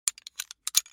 دانلود صدای کلیک 26 از ساعد نیوز با لینک مستقیم و کیفیت بالا
جلوه های صوتی